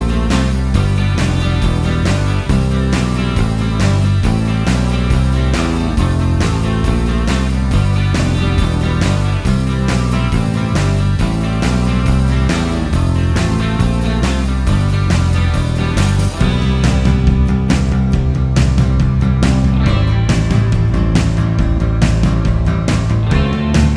Minus Solo Guitar Pop (1980s) 3:15 Buy £1.50